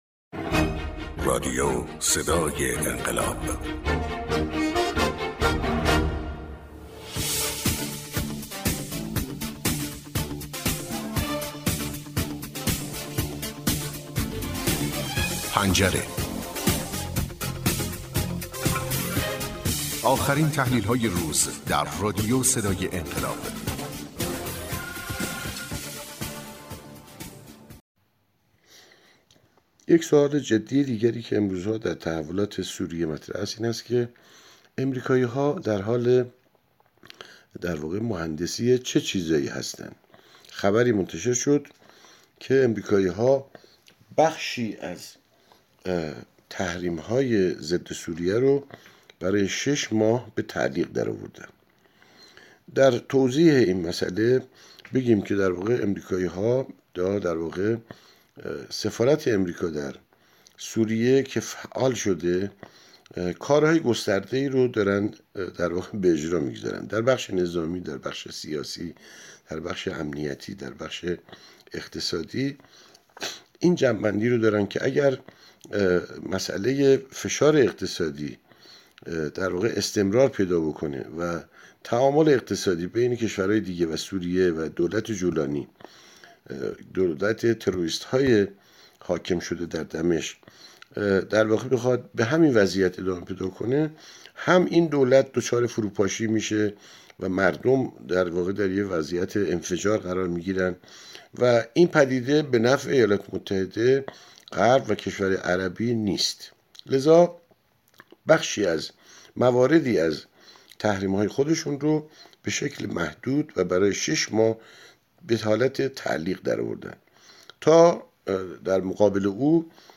کارشناس: